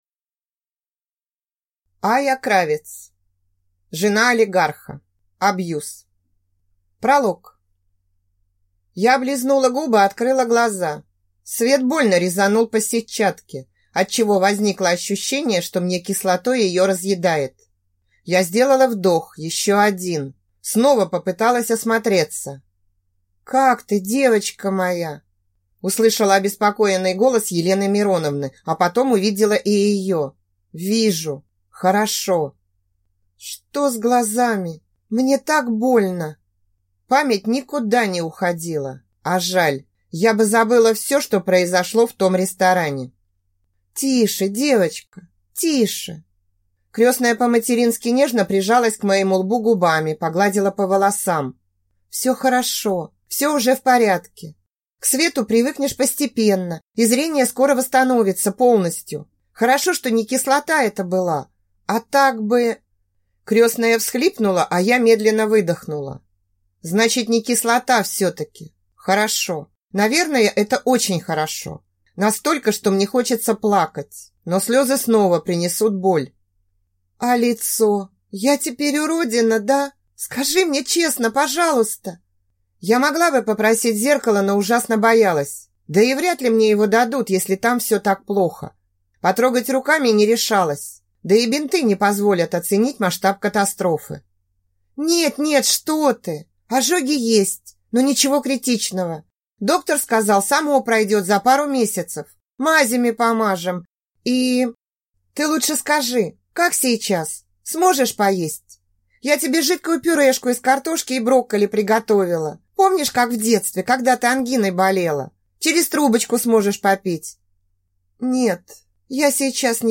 Аудиокнига Жена олигарха. Абьюз | Библиотека аудиокниг